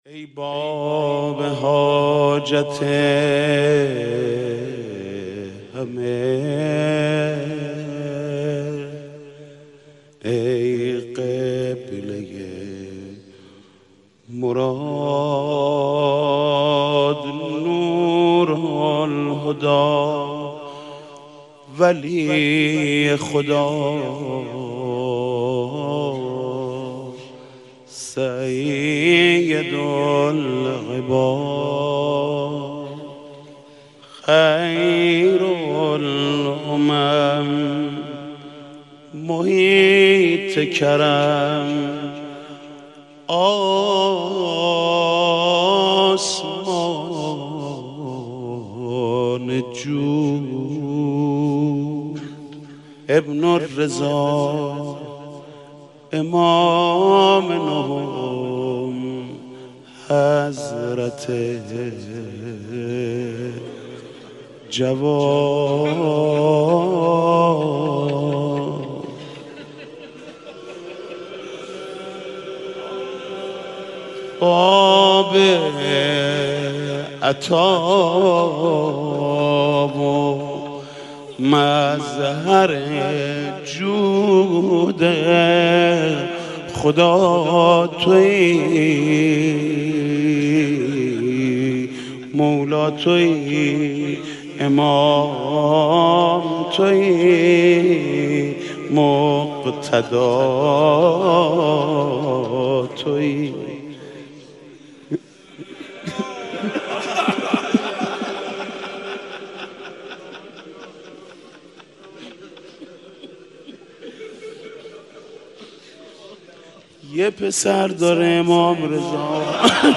«شهادت امام جواد 1389» روضه: ای باب حاجت همه